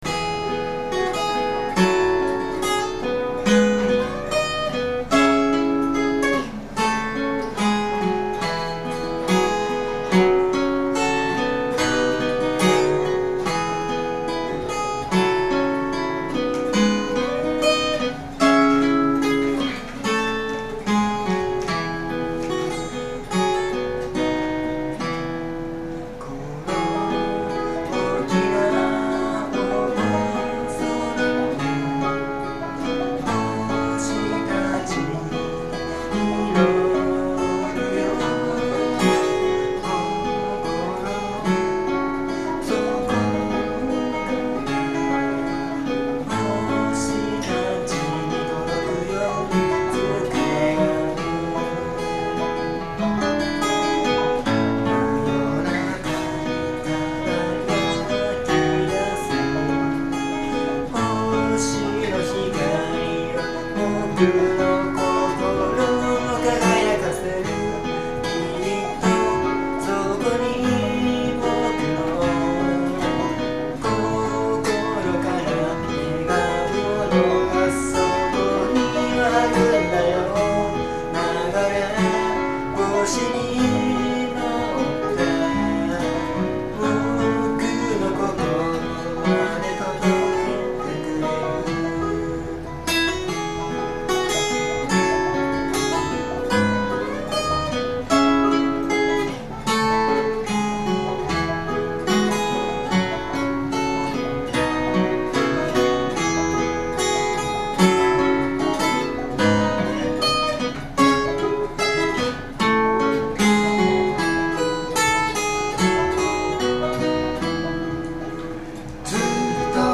Bluegrass style Folk group
Key of E→F#
しっとりとした曲調と歌詞のイメージから、最近はエンディングテーマにもなっています。
企画名: Studio Live III
録音場所: 与野本町BIG ECHO
リードボーカル、ギター
バンジョー